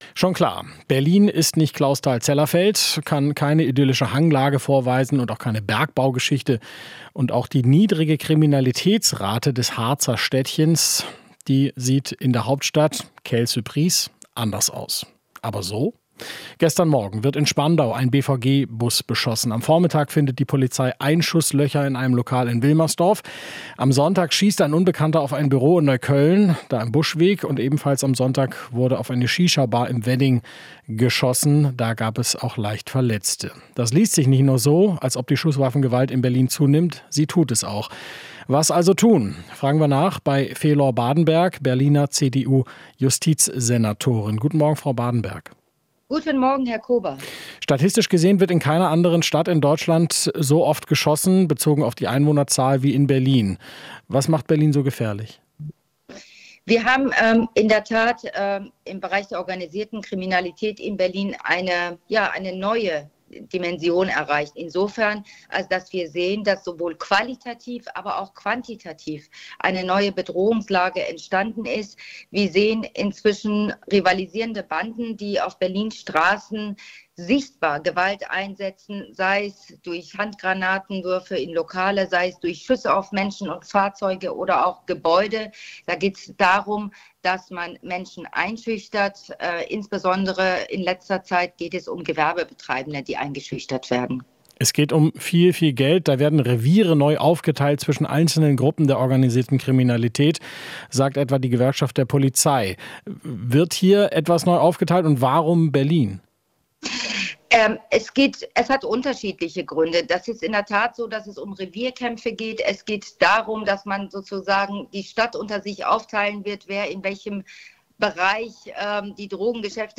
Berlins Justizsenatorin Felor Badenberg (CDU) spricht